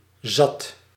Voiced alveolar fricative - Wikipedia
Retracted alveolar
Dutch[30] zat
[ˈz̺ɑtʰ] 'full', 'fed (up)'